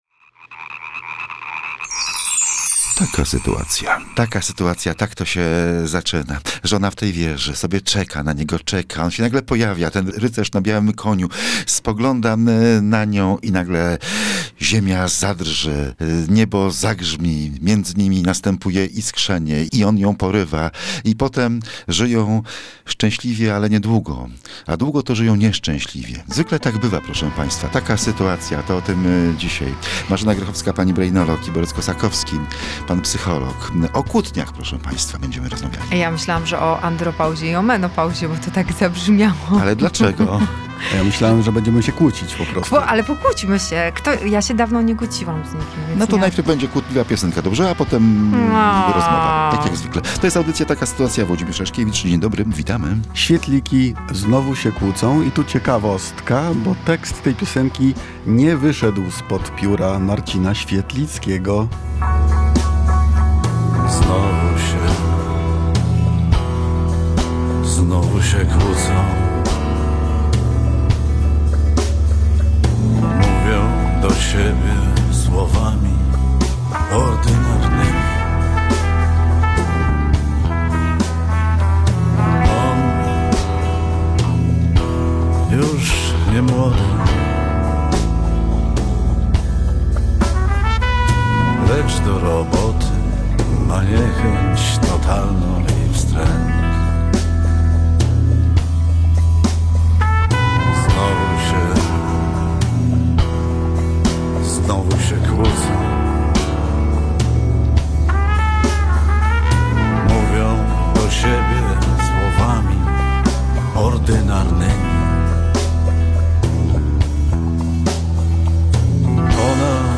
Kłótni nie unikniemy. Rozmowa o sprzeczkach, które mają sens i tych bez sensu